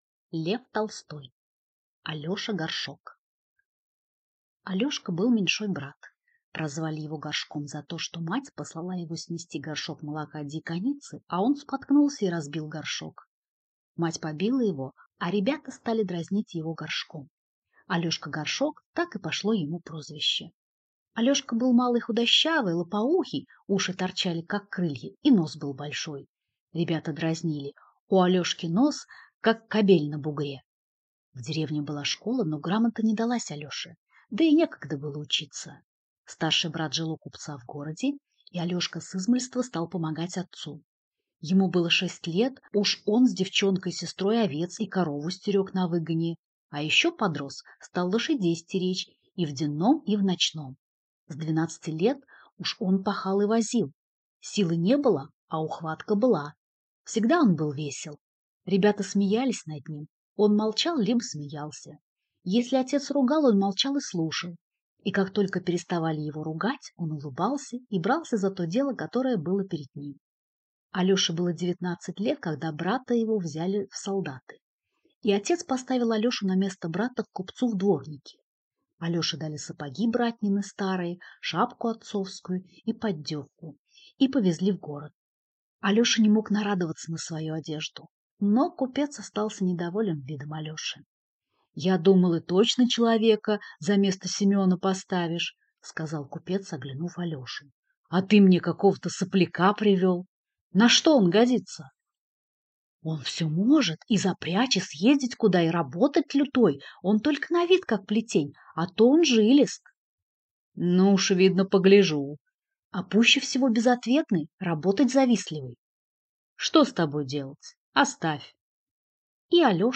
Аудиокнига Алеша Горшок | Библиотека аудиокниг